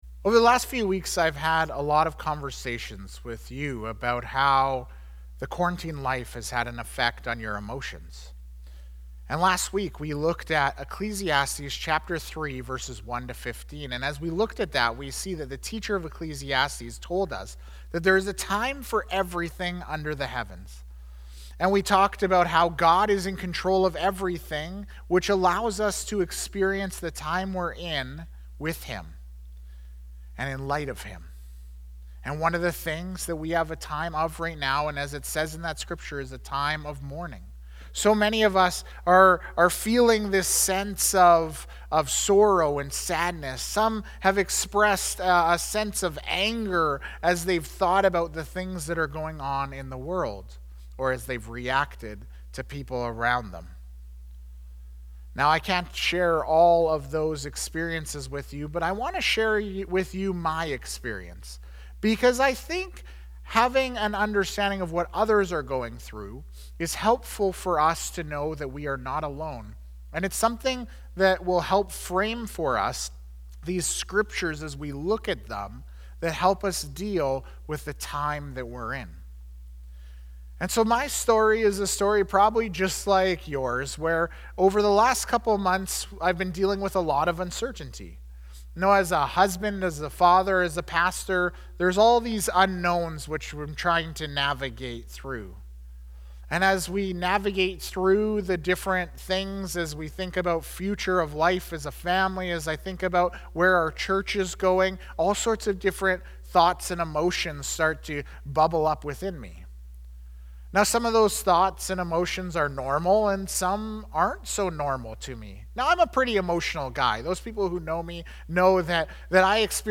Immanuel Church Sermons | Immanuel Fellowship Baptist Church